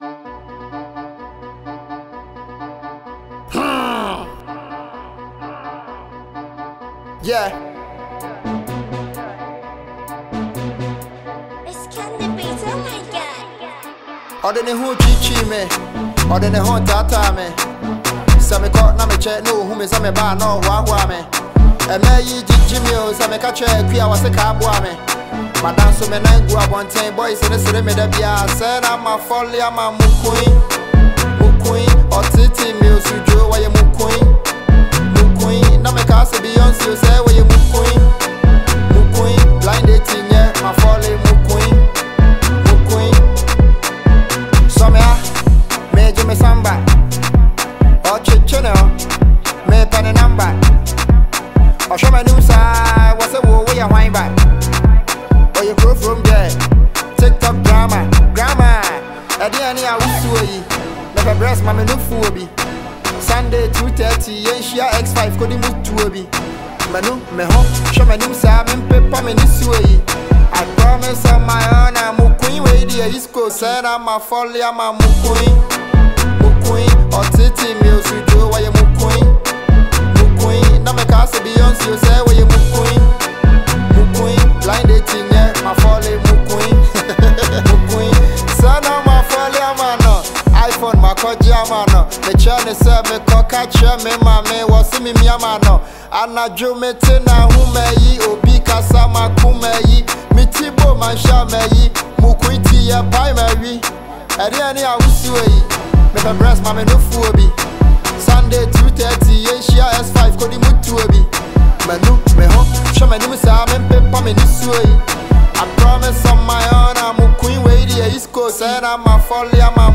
is raw, vibrant, and unapologetically local.